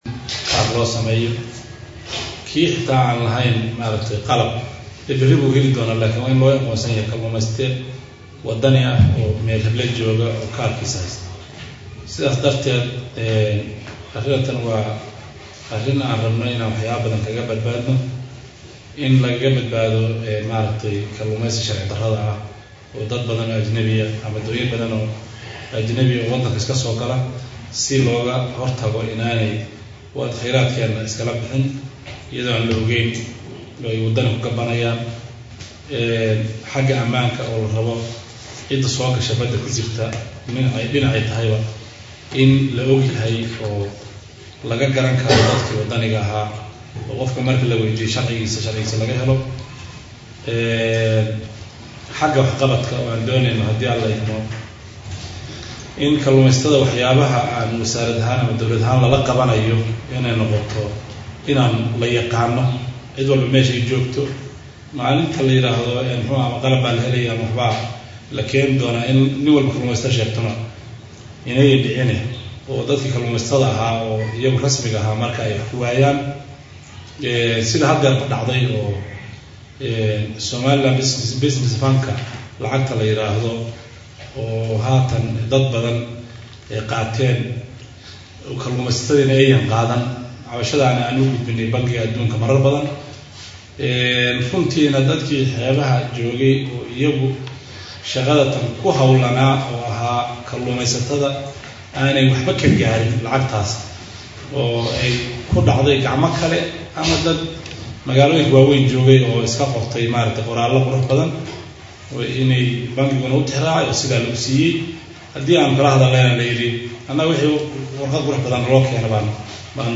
Hargaysa 03.April 2014 (SDN)- Wasiirka wasaaradda Kaluumaysiga Md Cali jaamac faarax (Buureed) ayaa maanta shir jaraa’id oo uu ku qabtay xafiiskiisa magaalada hargaysa waxa uu ku dhawaaqay qorshaha diiwaangelinta kaluumaysatada.